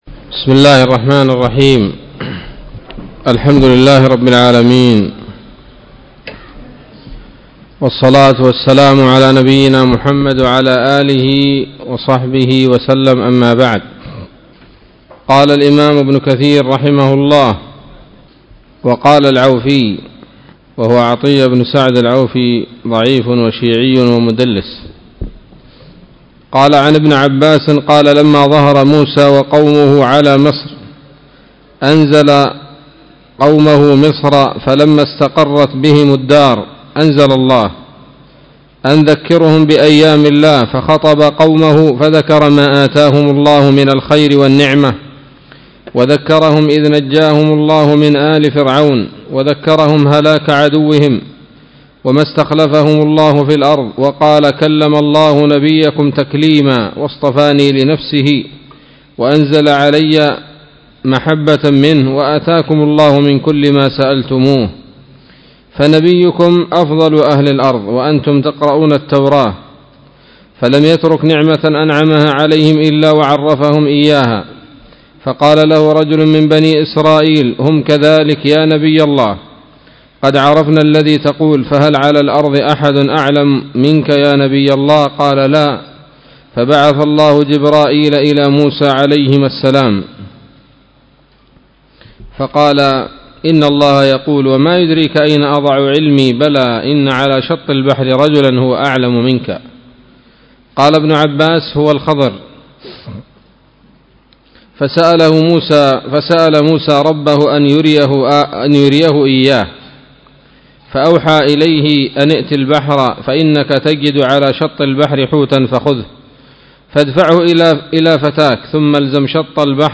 الدرس الثامن عشر من سورة الكهف من تفسير ابن كثير رحمه الله تعالى